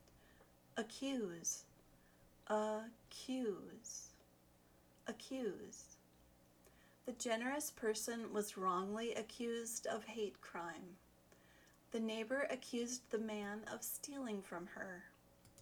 accuse.mp3